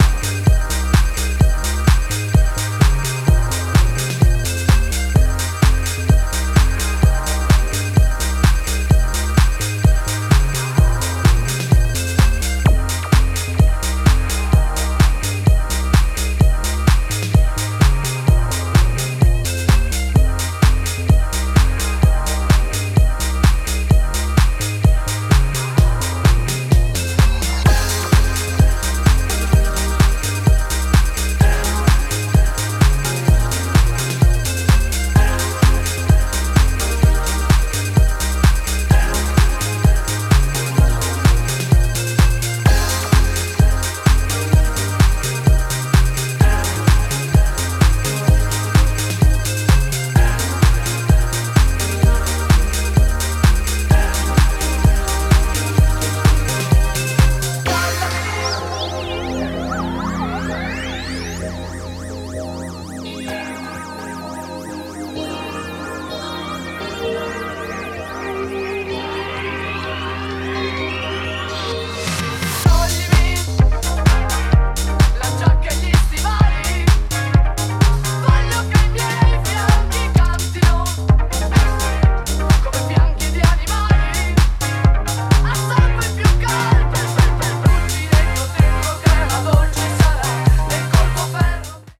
70年代イタリア産ファンク/ディスコ音源をファットに調理した、オブスキュア&パワー型エディットを仕上げてきました。